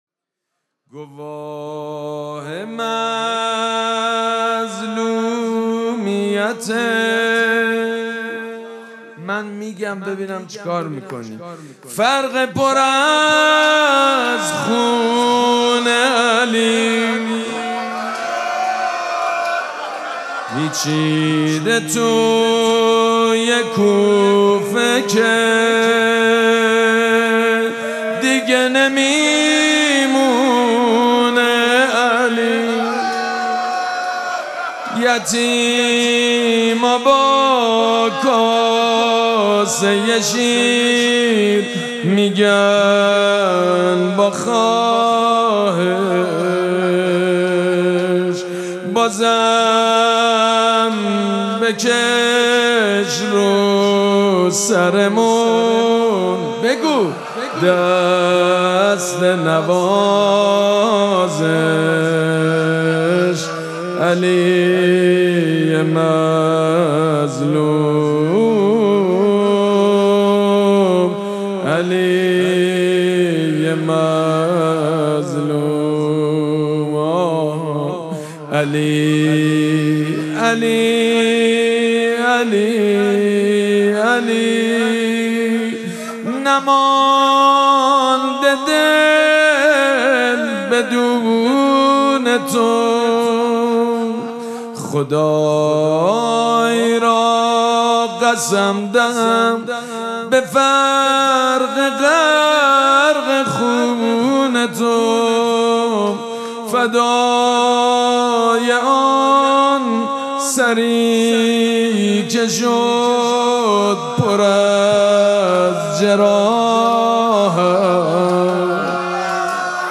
مراسم مناجات شب بیست و یکم ماه مبارک رمضان جمعه ۱ فروردین ماه ۱۴۰۴ | ۲۰ رمضان ۱۴۴۶ ‌‌‌‌‌‌‌‌‌‌‌‌‌هیئت ریحانه الحسین سلام الله علیها
سبک اثــر روضه مداح حاج سید مجید بنی فاطمه
roze.mp3